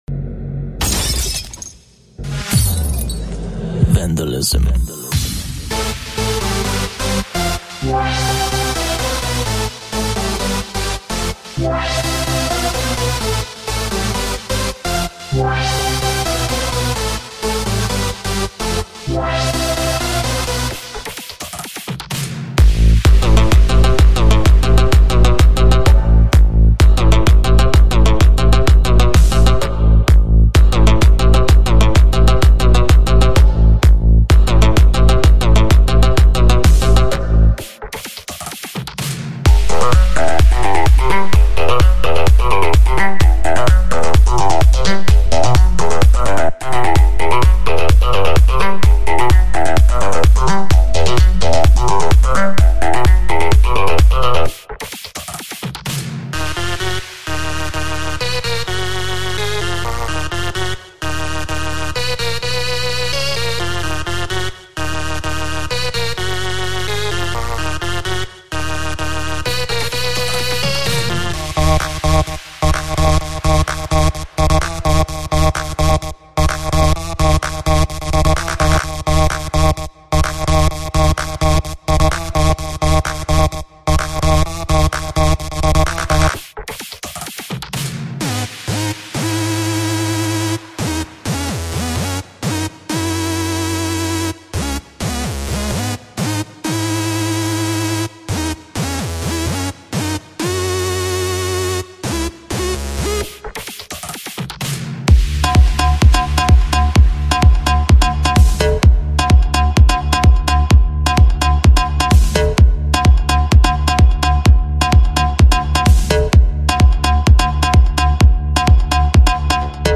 Vandalism-Shocking-EDM-For-Z3ta+2-Demo.mp3